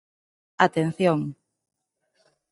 Προφέρεται ως (IPA)
/atenˈθjoŋ/